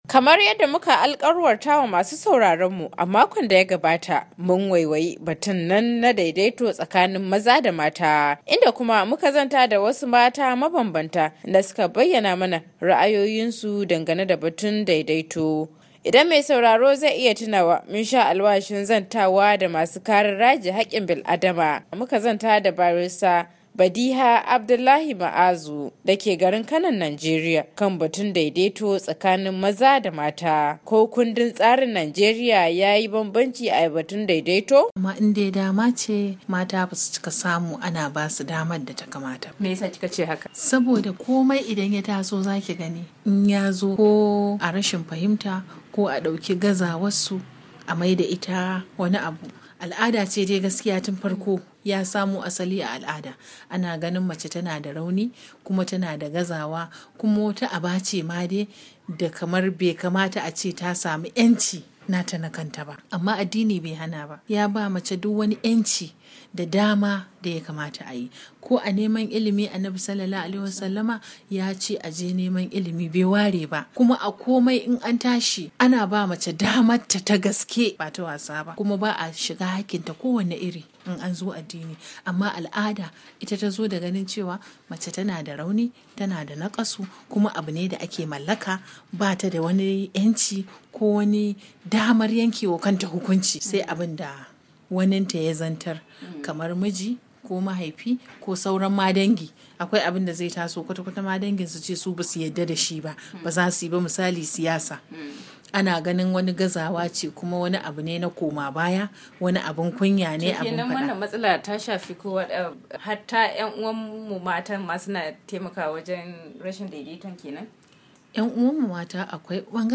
Kamar yadda muka alkwarta masu sauraronmu a makon da ya gaba mun waiwayi batun nan ne na daidaito tsakani maza da mata , inda kuma muka zanta da wasu mata mabanbanta suka kuma bayyana mana ra’ayoyinsu dangane da batun daidaito.